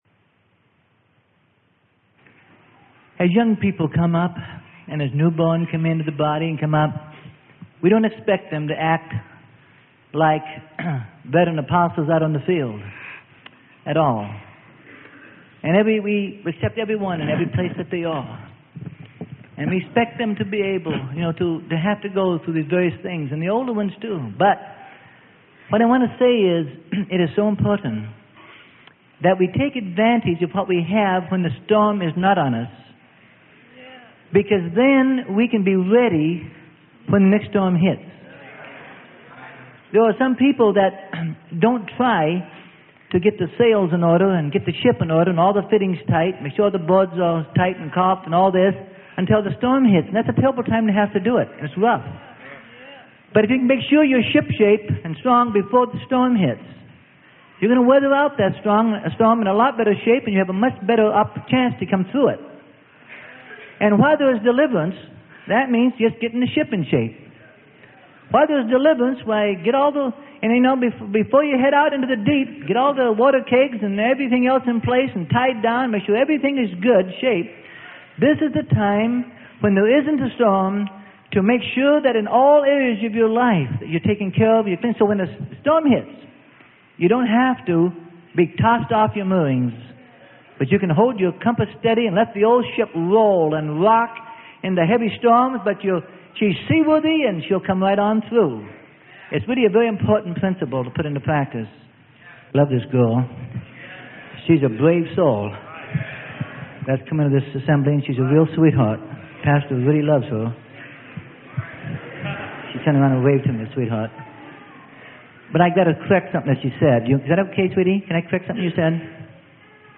Sermon: Pastoral Perspectives - Freely Given Online Library